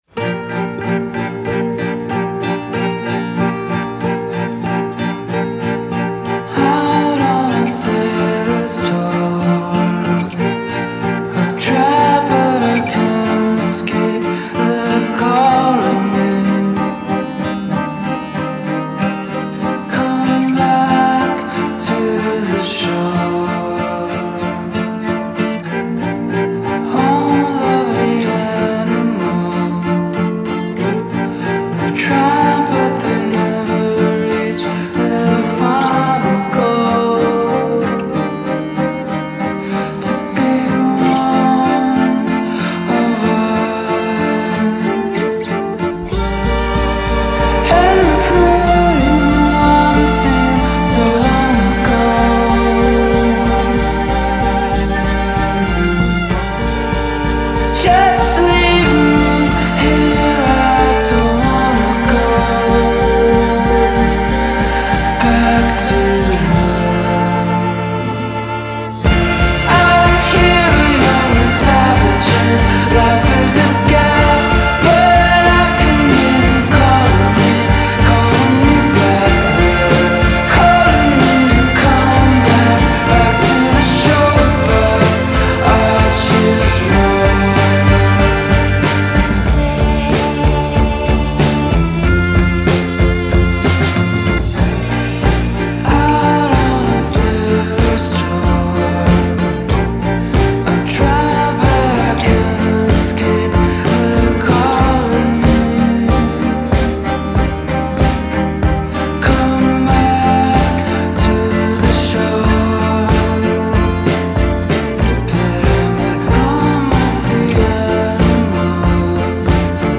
21st-century indie rocker